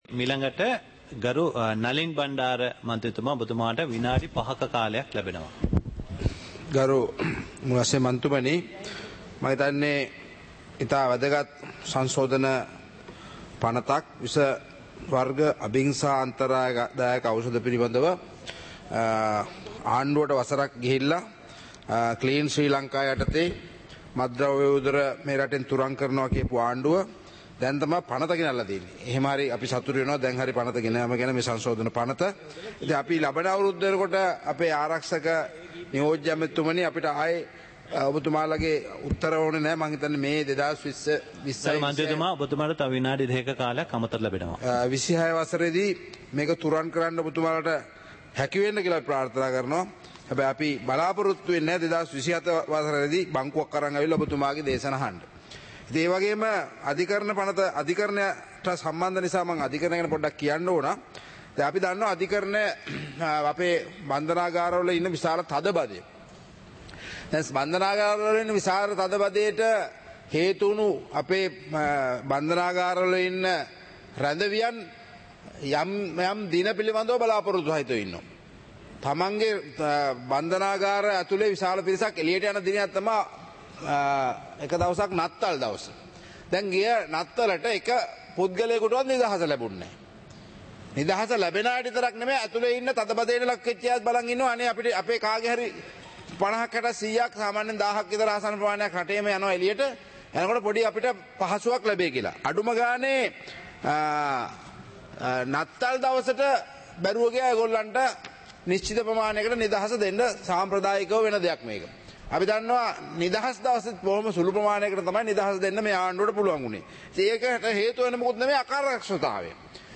சபை நடவடிக்கைமுறை (2026-02-19)